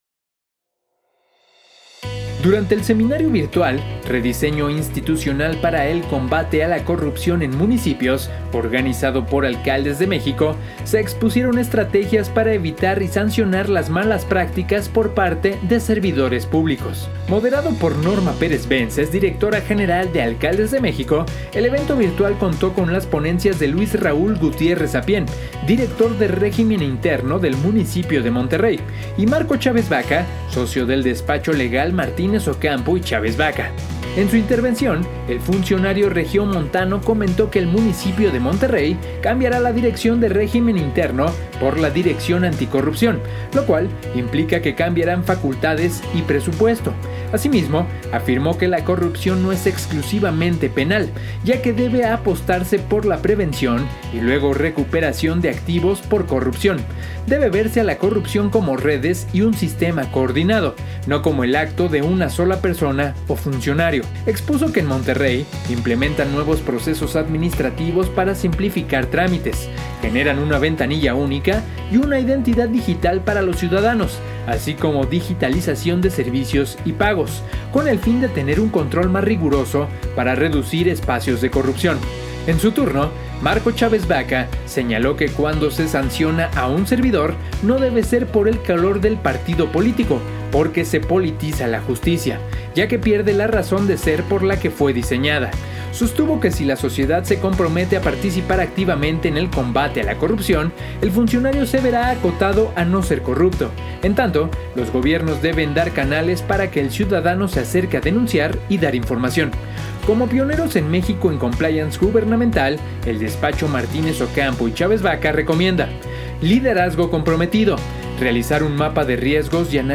Durante el seminario virtual “Rediseño institucional para el combate a la corrupción en municipios”, organizado por Alcaldes de México, se expusieron estrategias para evitar —y sancionar— malas prácticas por parte de servidores públicos.